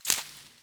harvest_4.wav